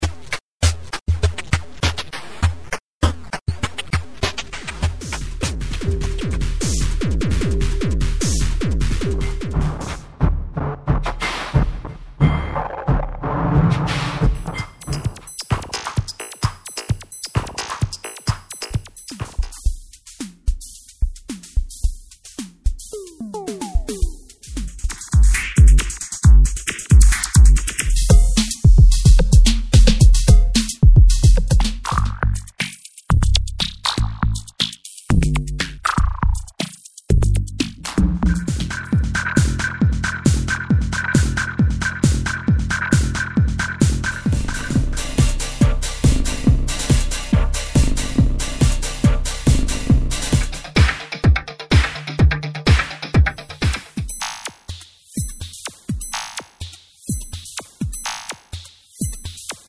Drum sounds loops Acid electro ambient IDM
Drum loops and Drum sounds for make electro, ambient, techno, IDm etc.
More than 60 drum loops from 90bmp to 150bmp. This loops are perfect to any electronic style of music.